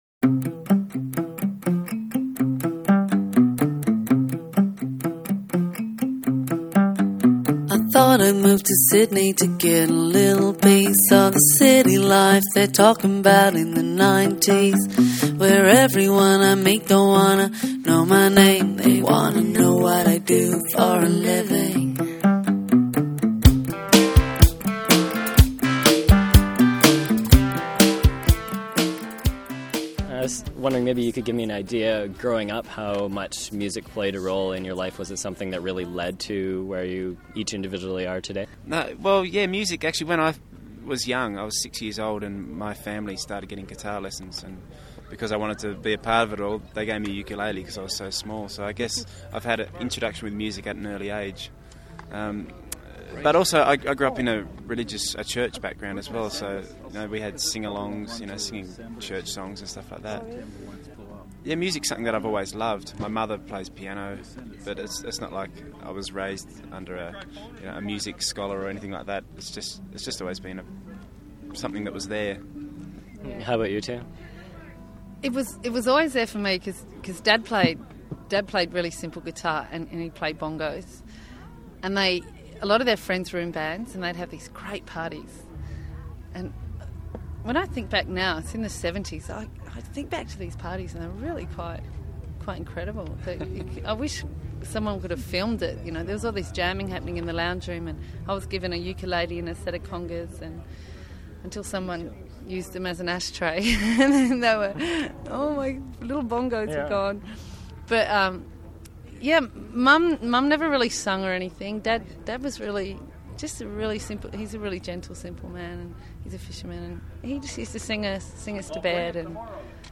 !earshot interview with The Waifs: